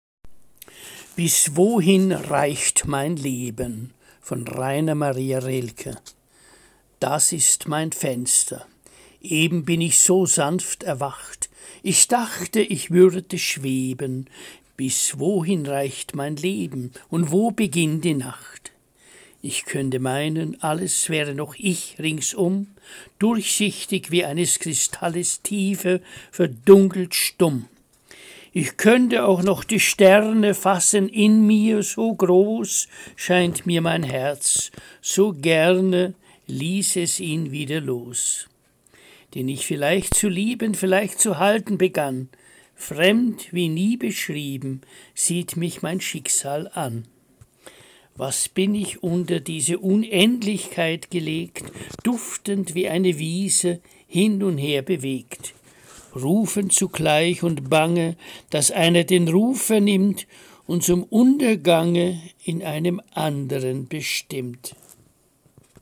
Lesung - Musikvideo